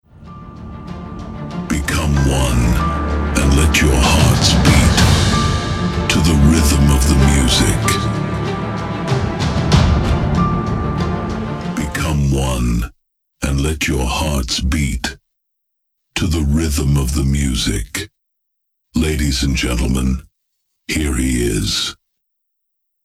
Get ready-made, professional DJ intros featuring your stage name created using cutting-edge AI vocals.
FULL TEXT (Acapella Voice Only)